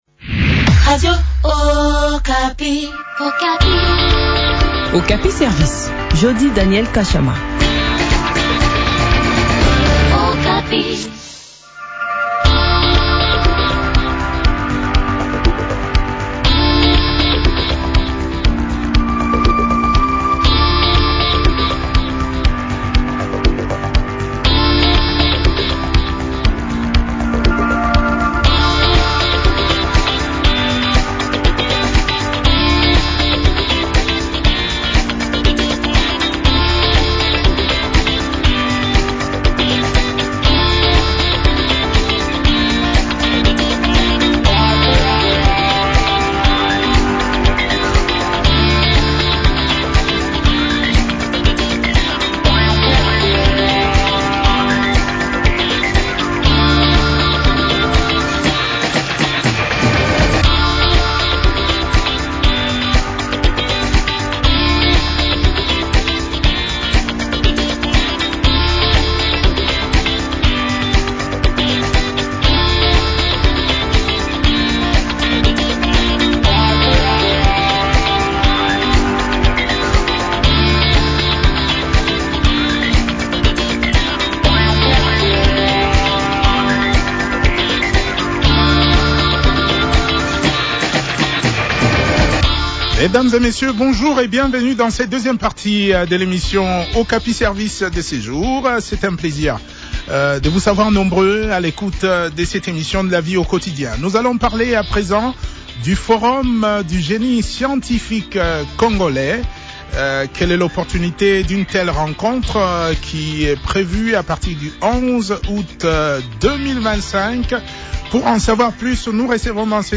ministre de la Recherche Scientifique et Innovation Technologique.